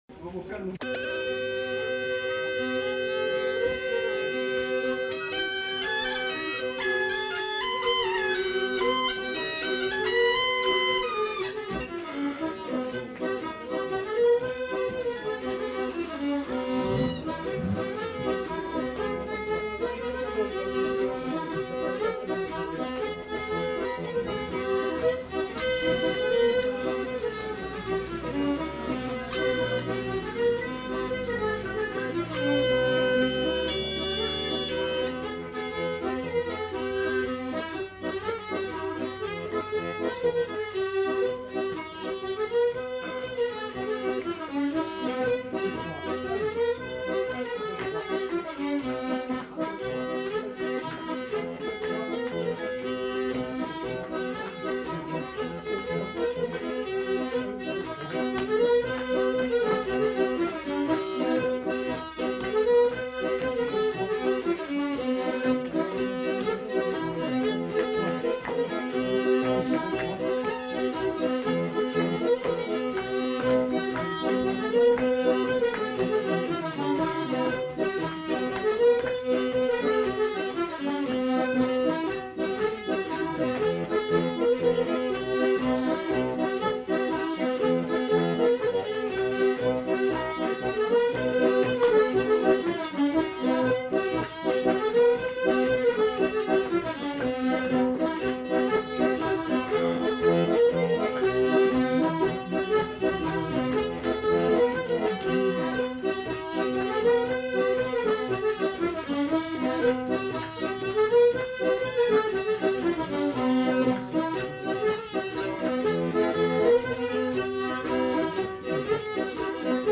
Perlinpinpin fòlc (ensemble instrumental)
Aire culturelle : Agenais
Lieu : Foulayronnes
Genre : morceau instrumental
Instrument de musique : accordéon diatonique ; boha ; violon
Danse : rondeau